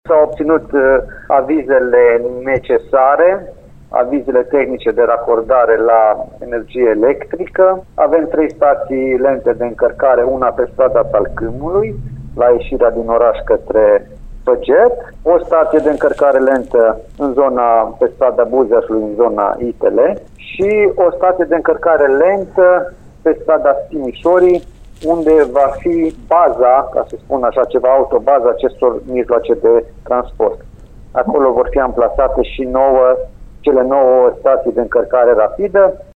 Niciunul din cele nouă autobuze electrice nu a ajuns până acum la Lugoj, deși livrarea lor trebuia să înceapă de la 1 martie, spune primarul interimar al Lugojului, Bogdan Blidariu.